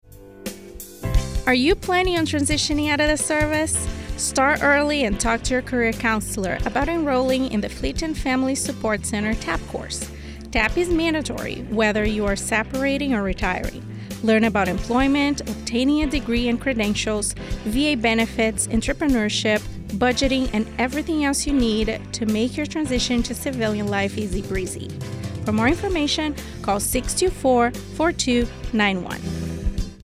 NAVAL AIR STATION SIGONELLA, Italy (Jan. 3, 2024) A radio spot describing the transition assistance program (TAP) offered through Fleet and Family Support Service Center on Naval Air Station Sigonella.